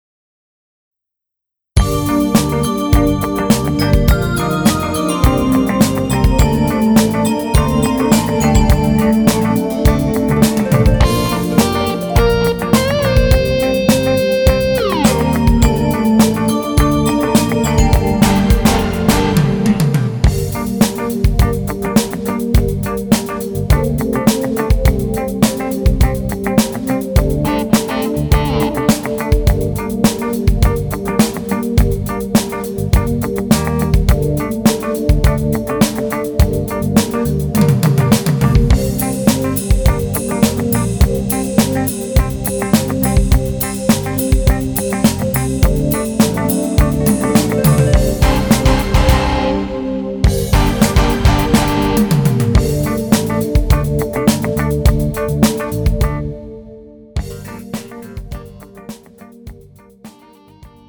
음정 남자-2키
장르 가요 구분 Pro MR